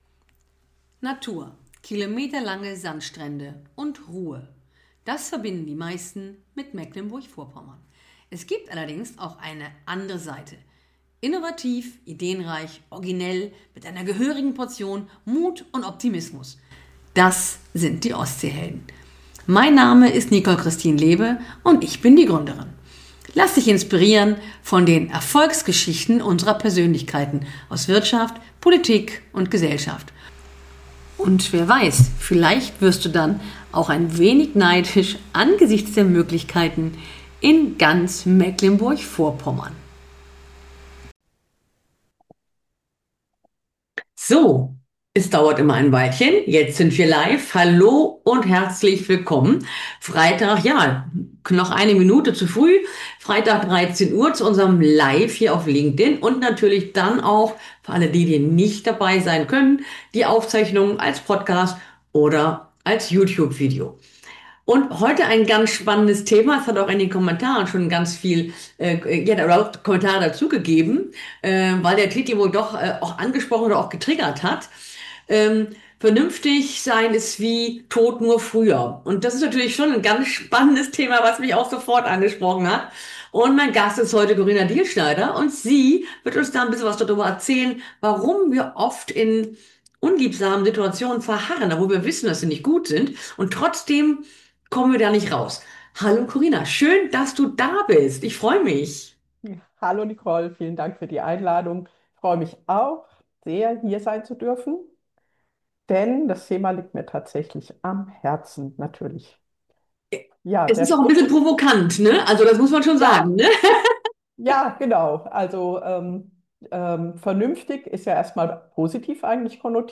Im Gespräch erfährst du, warum du bleibst – und was dich wieder in Bewegung bringen kann.